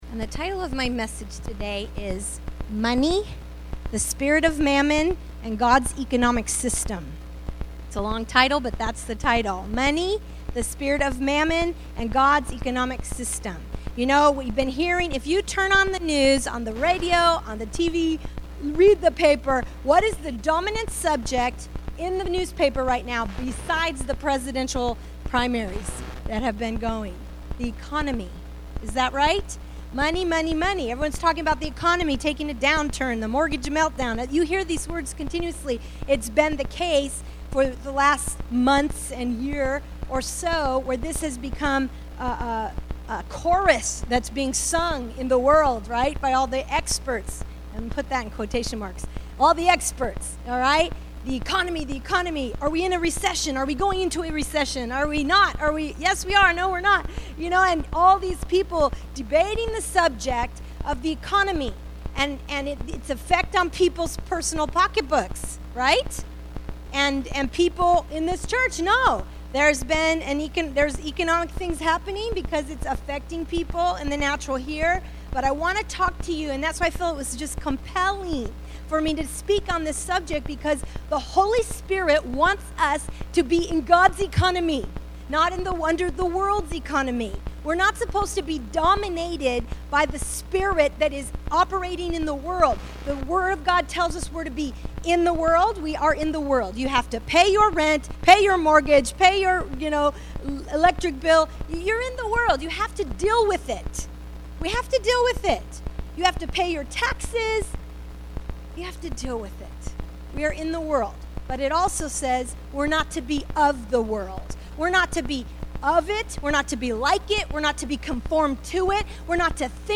Single Bible Teachings